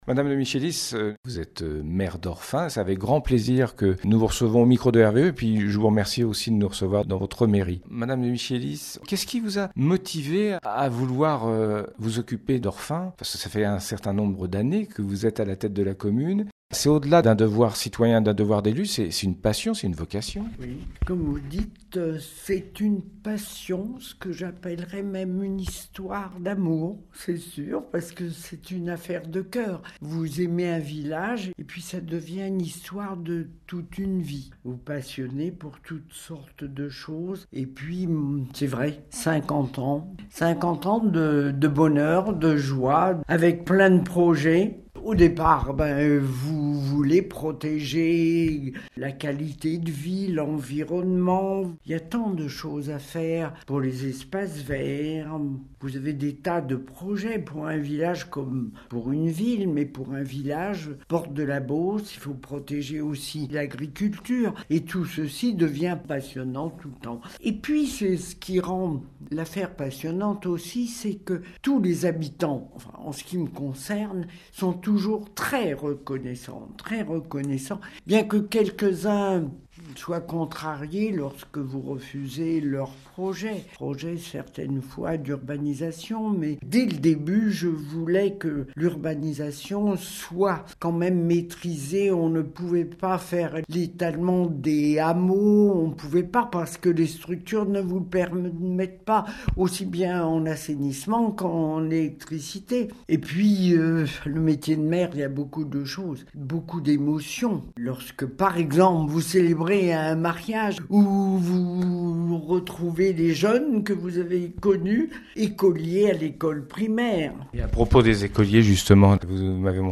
Interview de Madame de Michelis Maire d'Orphin - Radio RVE